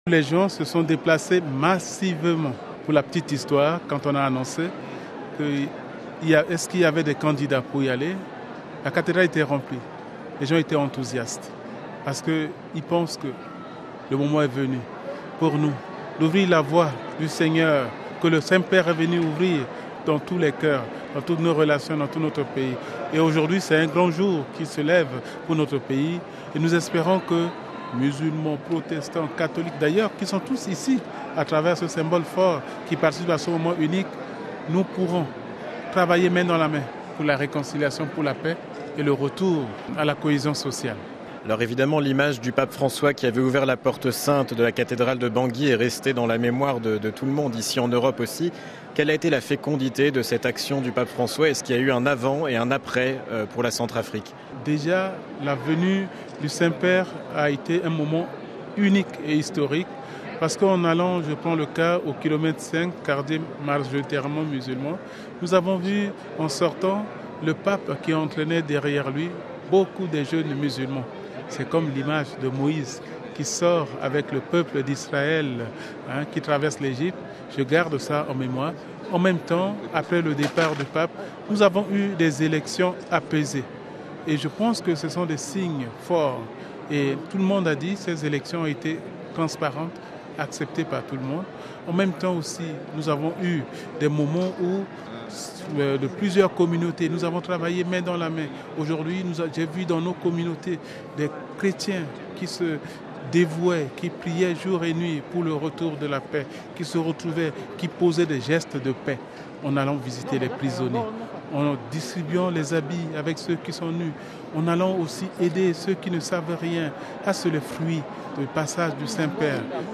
(RV) Entretien - Parmi les 17 nouveaux cardinaux créés ce 19 novembre par le Pape François se détache la figure du cardinal Dieudonné Nzapalainga.